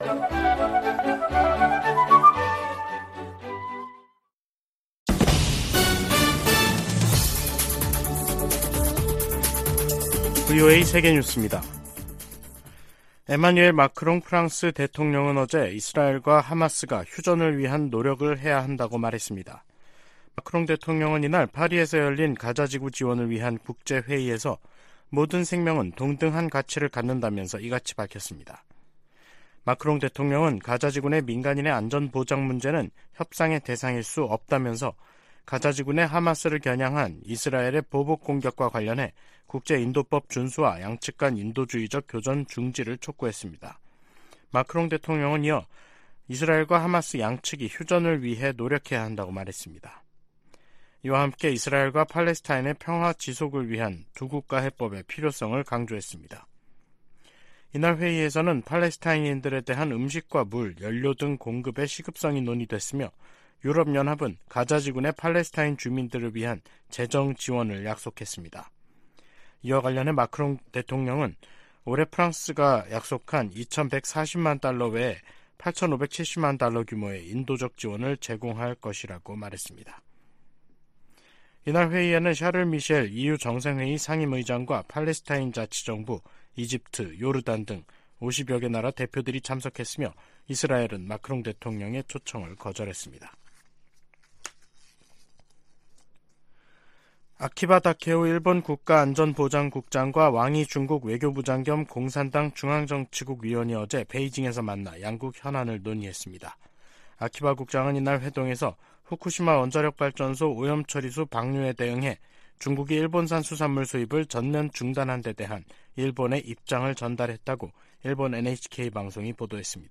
VOA 한국어 간판 뉴스 프로그램 '뉴스 투데이', 2023년 11월 11일 2부 방송입니다. 토니 블링컨 미국 국무장관이 윤석열 한국 대통령과 만나 북한과 우크라이나, 가자지구 문제 등 양국 현안을 논의했다고 국무부가 밝혔습니다. 미국 정부가 북한을 비호하는 러시아의 태도를 비판하면서 북한 정권에 분명한 메시지를 전할 것을 촉구했습니다.